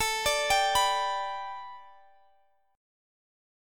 Listen to A7sus2sus4 strummed